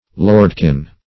lordkin - definition of lordkin - synonyms, pronunciation, spelling from Free Dictionary Search Result for " lordkin" : The Collaborative International Dictionary of English v.0.48: Lordkin \Lord"kin\, n. A little lord.